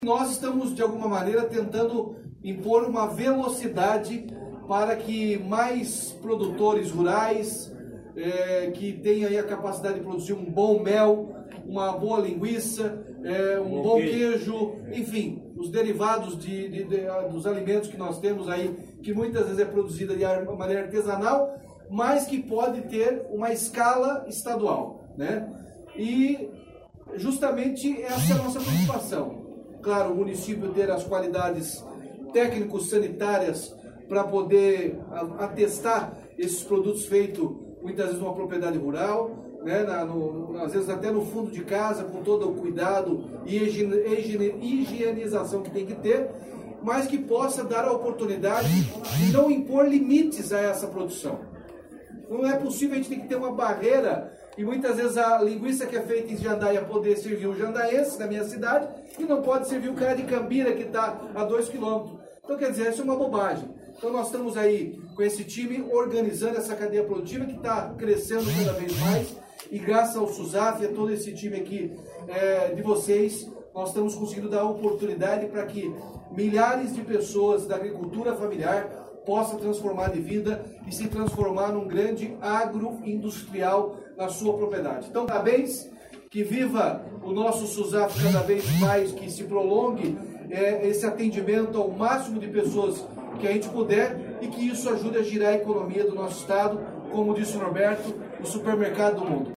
Sonora do governador Ratinho Junior sobre a adesão ao Susaf por parte de três municípios do Oeste do Estado